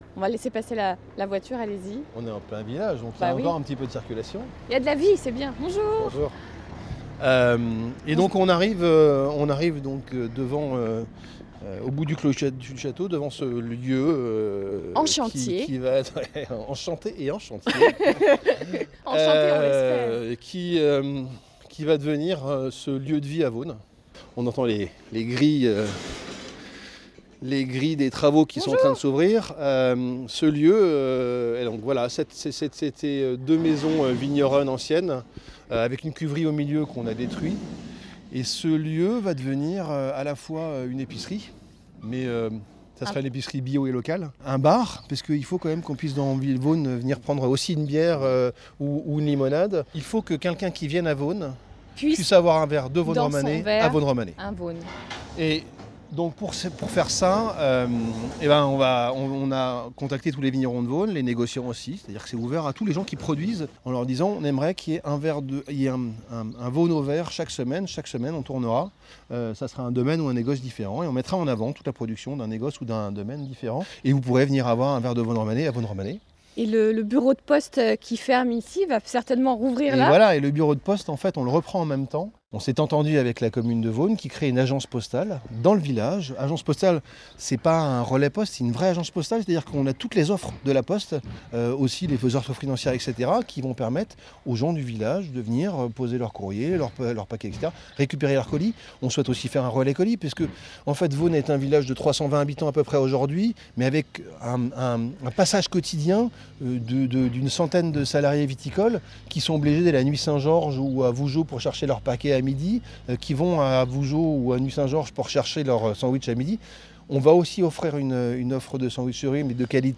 Vendredi 25 février 2022, nous accueillions France Bleu Bourgogne au Domaine : un reportage à écouter pour découvrir notre nouveau projet…